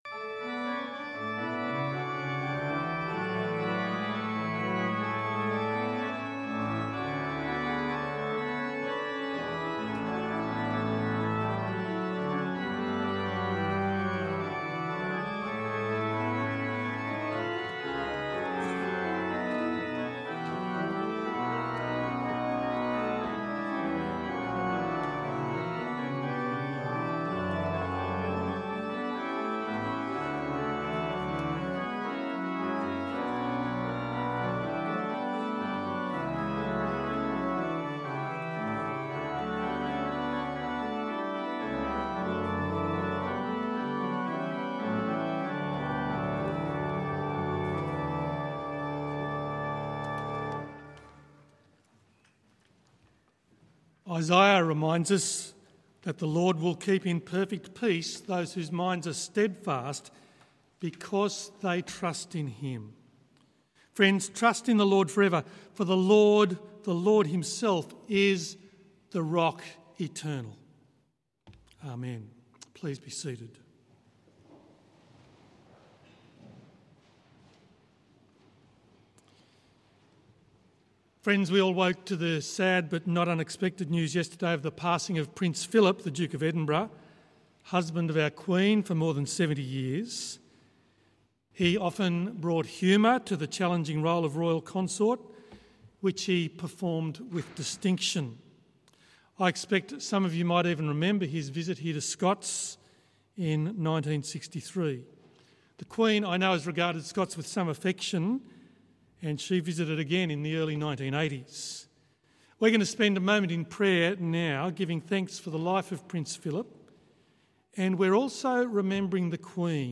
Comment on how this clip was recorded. The Scots’ Church Melbourne 11am Service 11th of April 2021